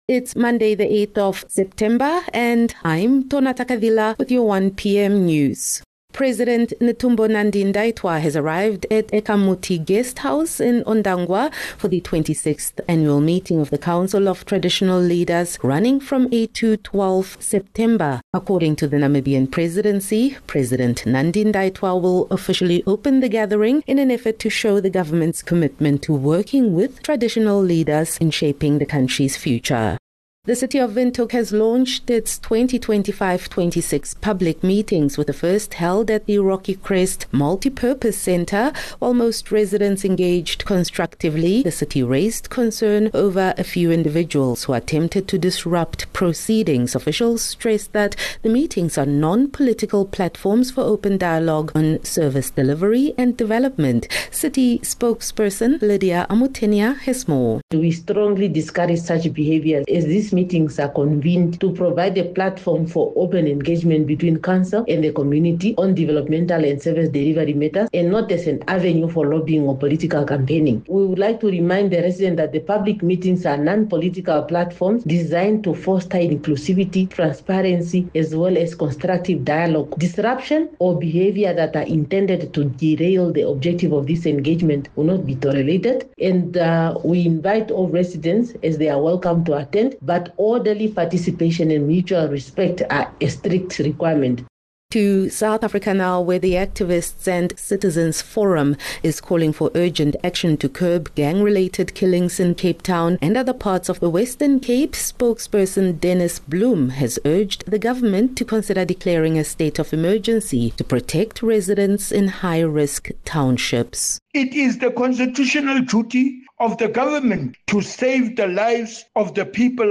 8 Sep 8 September - 1 pm news